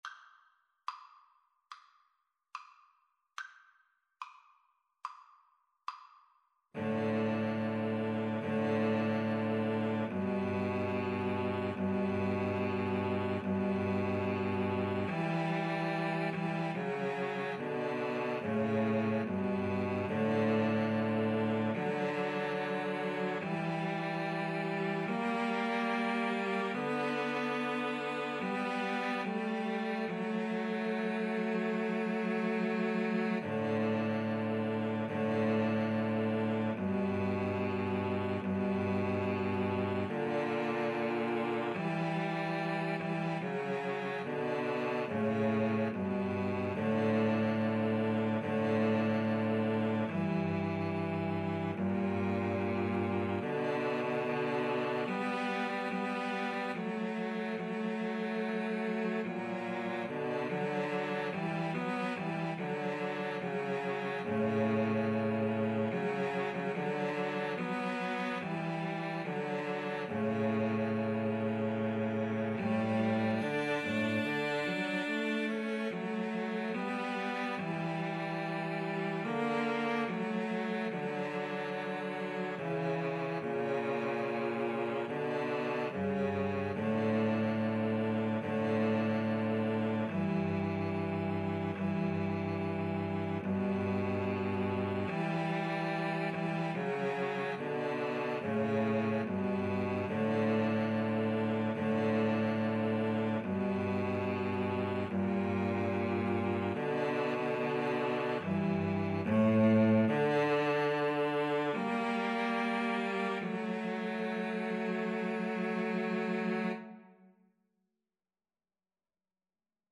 "Deep River" is an anonymous spiritual of African American origin.
4/4 (View more 4/4 Music)
D major (Sounding Pitch) (View more D major Music for Cello Trio )
Andante =c.72
Cello Trio  (View more Easy Cello Trio Music)
Traditional (View more Traditional Cello Trio Music)